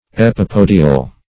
Meaning of epipodial. epipodial synonyms, pronunciation, spelling and more from Free Dictionary.
Search Result for " epipodial" : The Collaborative International Dictionary of English v.0.48: Epipodial \Ep`i*po"di*al\, a. 1.